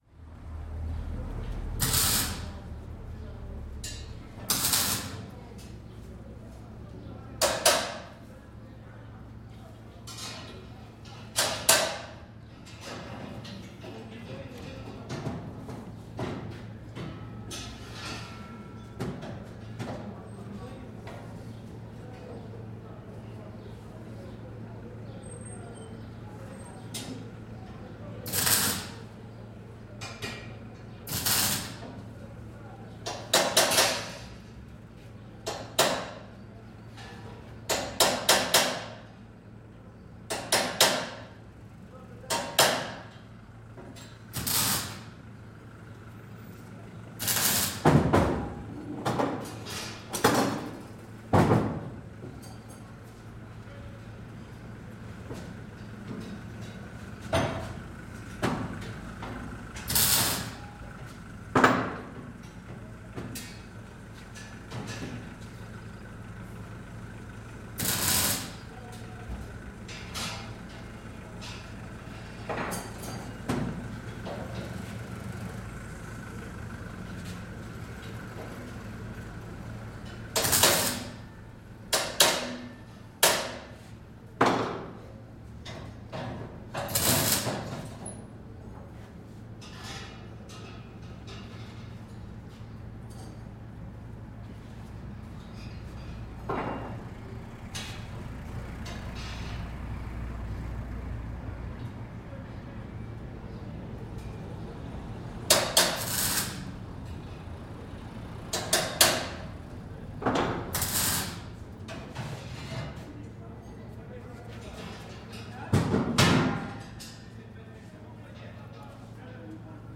NODAR.00115 – Viseu: Rua do Arco – Serralheiros trabalham
Registo do som de uma serralharia numa das ruas mais tradicionais de Viseu. Gravado com Edirol R44 e um par de microfones de lapela Audio-Technica AT899.
Tipo de Prática: Paisagem Sonora Rural
Viseu-Rua-do-Arco-Serralheiros-trabalham.mp3